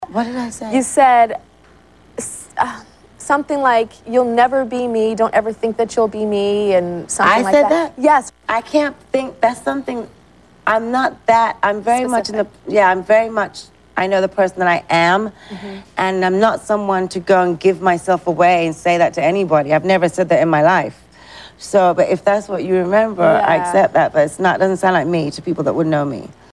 Today’s gem: a one-on-one with supermodel Naomi Campbell.
tyra_naomi_rambles.mp3